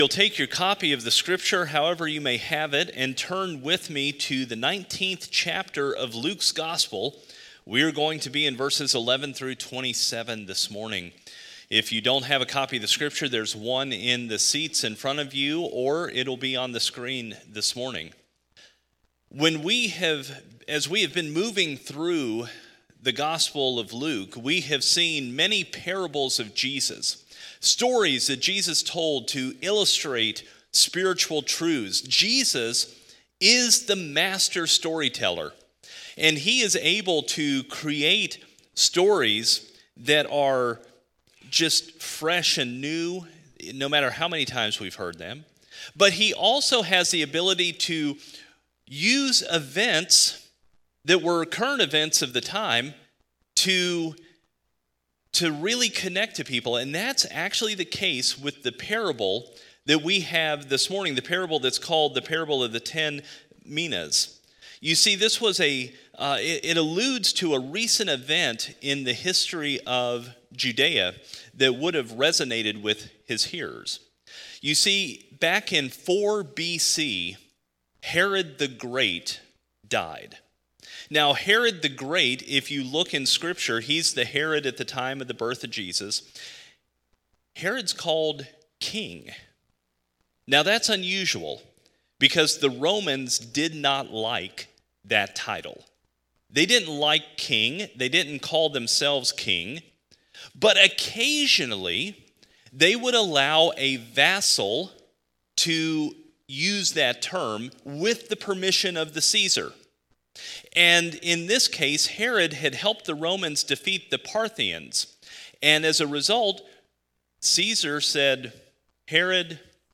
Sermons by Faith Baptist Church